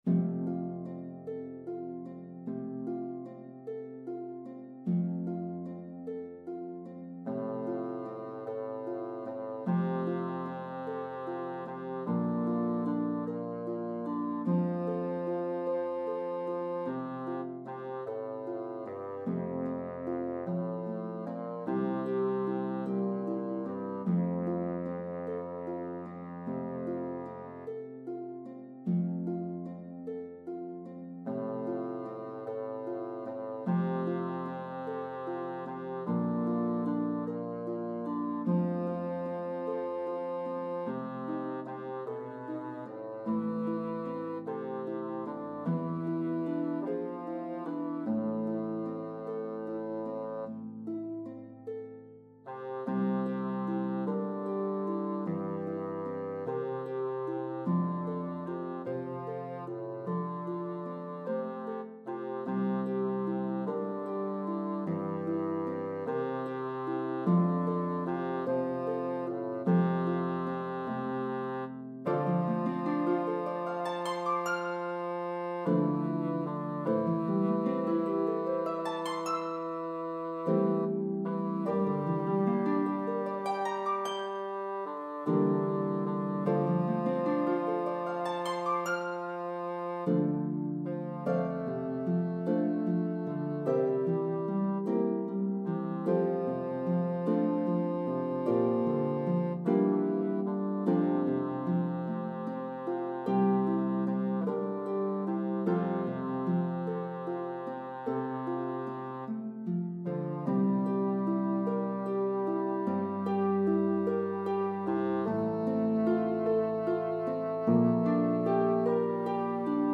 The full range of the Pedal Harp
The melody of the two verses varies in rhythm & pitches.